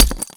grenade_hit_concrete_02.WAV